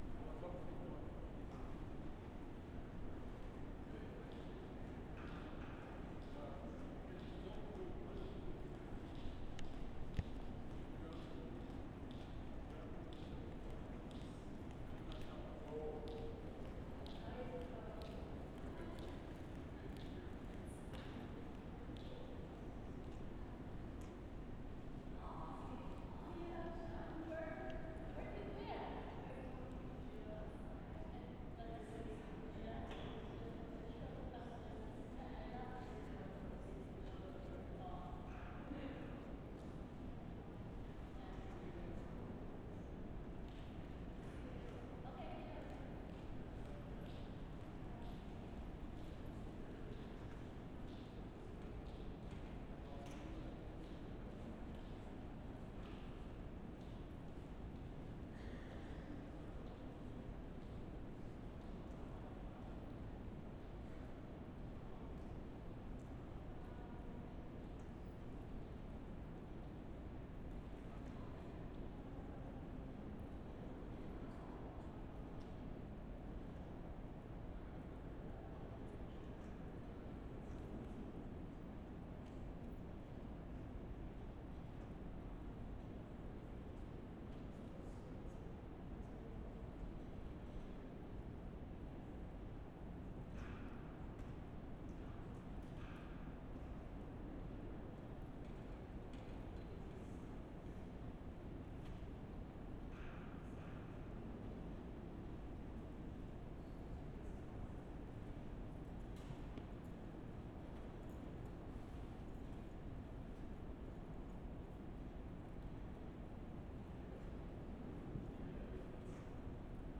MUSEUM OF ANTHROPOLOGY, JULY 15/2010
Inside the MOA, 3:49
4. Footsteps at 10 sec, not many people around as it is set to close at 5pm (within 15 mins of this recording), moderate amount of activity taking place - camera sound (beeps & shutters), chatter amongst guests, footsteps.